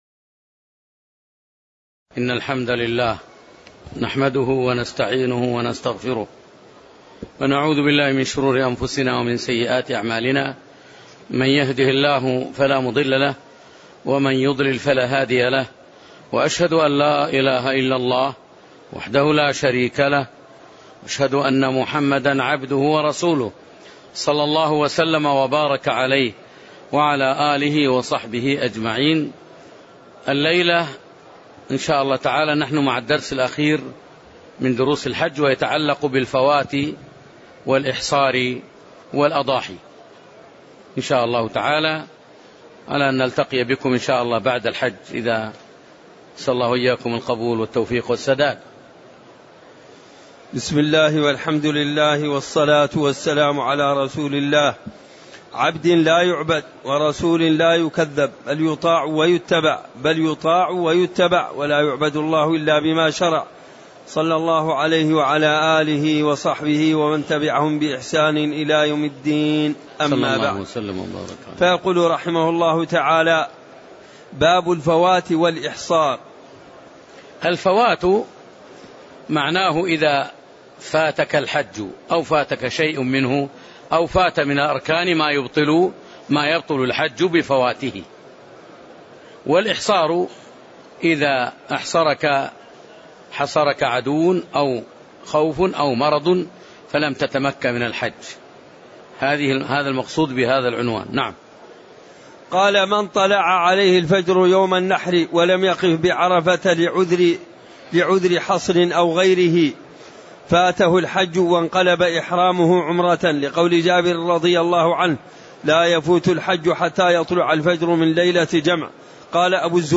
تاريخ النشر ٥ ذو الحجة ١٤٣٨ هـ المكان: المسجد النبوي الشيخ